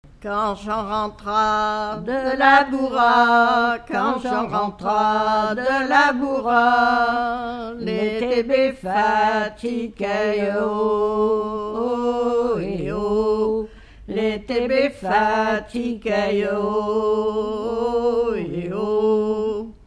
Mémoires et Patrimoines vivants - RaddO est une base de données d'archives iconographiques et sonores.
en duo
Genre laisse